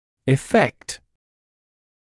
[ɪ’fekt][и’фэкт]эффект, результат, следствие